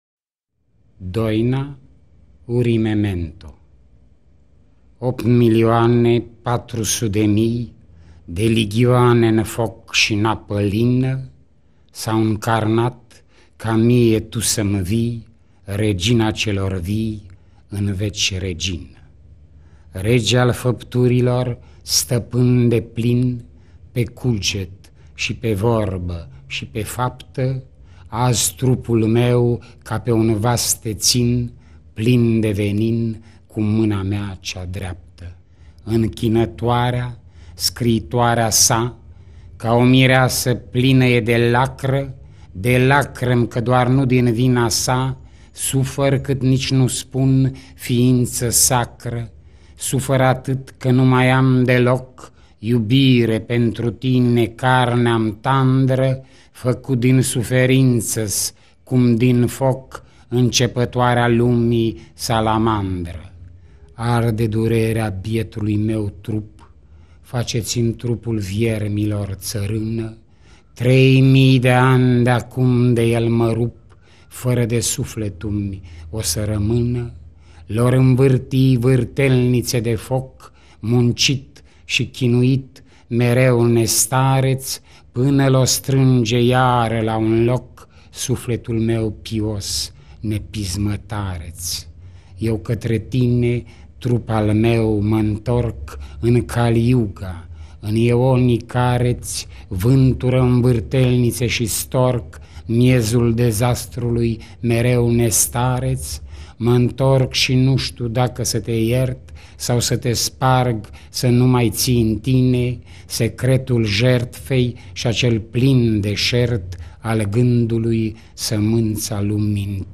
Cezar-Ivanescu-poem.mp3